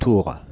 Ääntäminen
Ääntäminen Paris: IPA: [tuʁ] Haettu sana löytyi näillä lähdekielillä: ranska Käännöksiä ei löytynyt valitulle kohdekielelle.